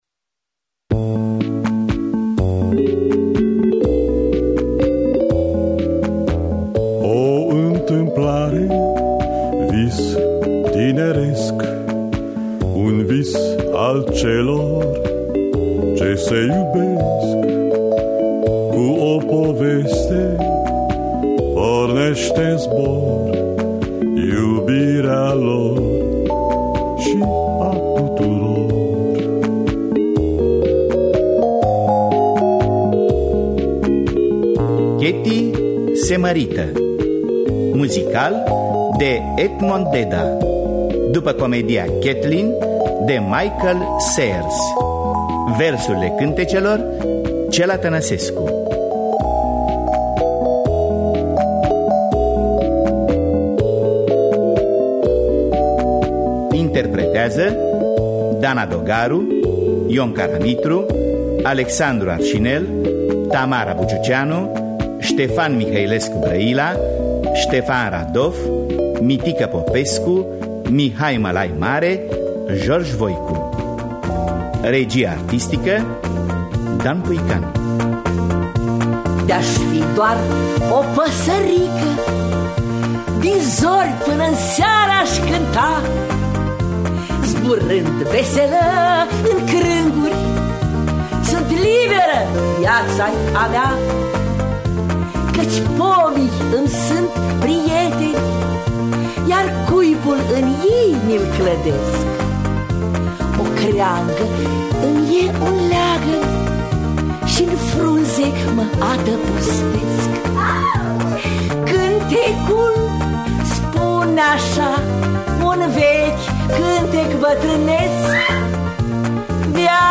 Ketty se marita musical de Edmond Deda.mp3